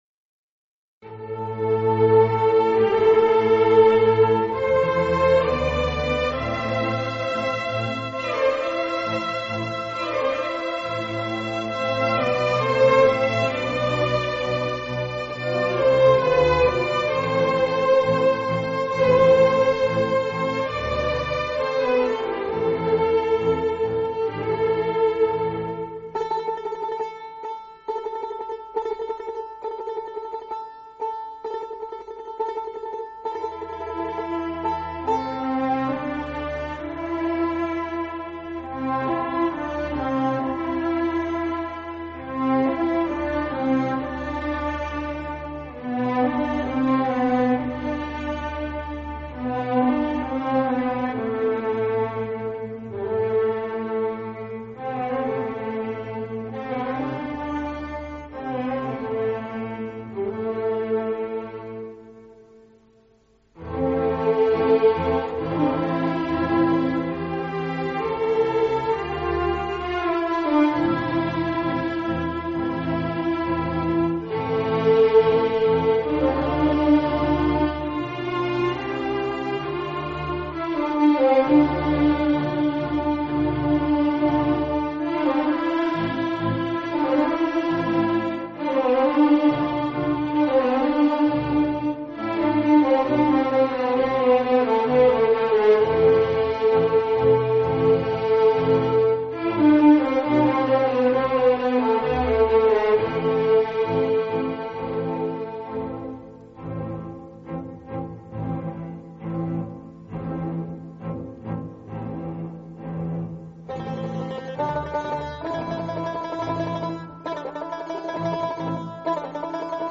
قطعه‌ی بی کلام ارکسترال/ آهنگساز: محمد بیگلری پور